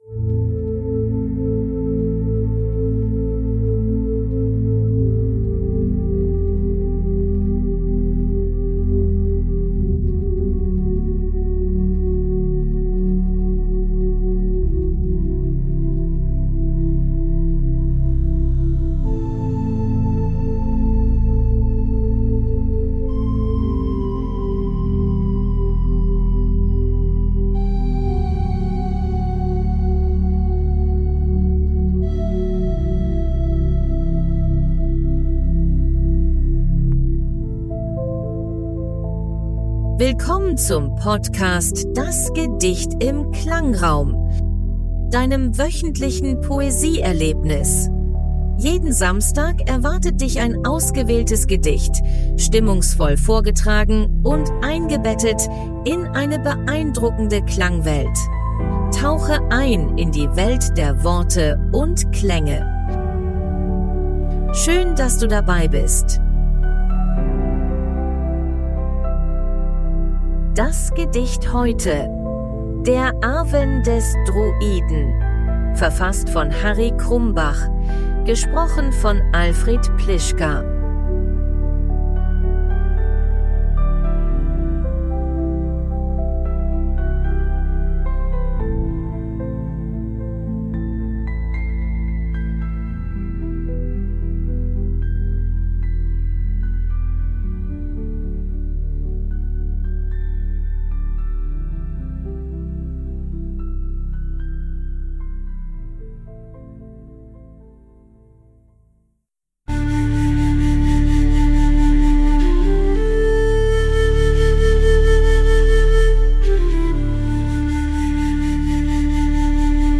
Klangraum: KI generiert.